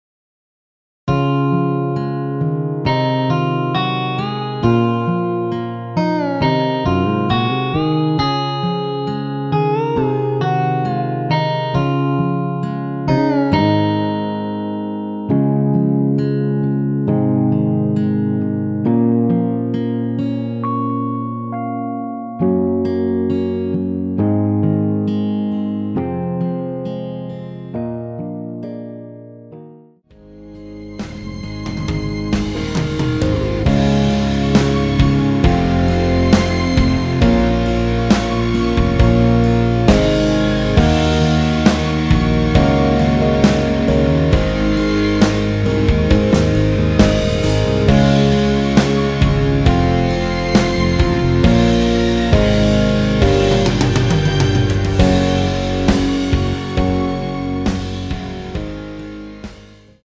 C#
앞부분30초, 뒷부분30초씩 편집해서 올려 드리고 있습니다.
중간에 음이 끈어지고 다시 나오는 이유는